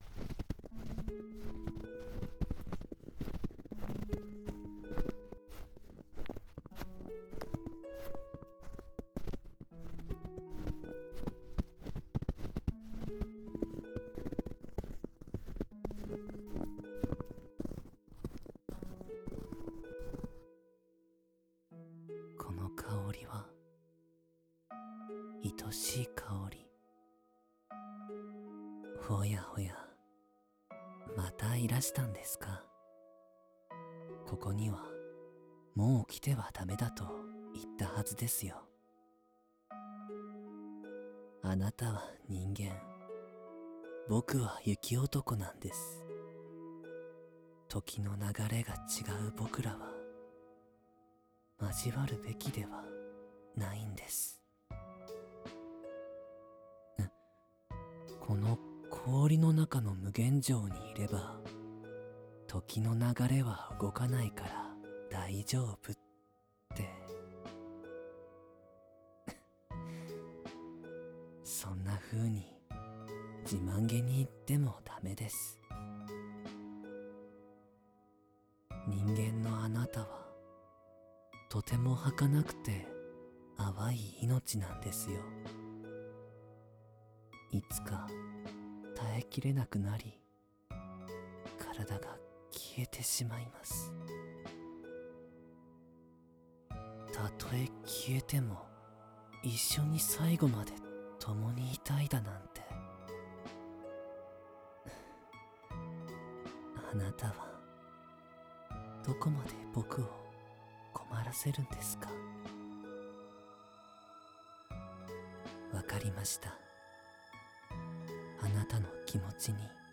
氷の中の無限城 雪男ver - ASMR Mirror
纯爱/甜蜜 情侣 严肃 幻想 纯爱 萌 治愈 系列作品 女性向 乙女向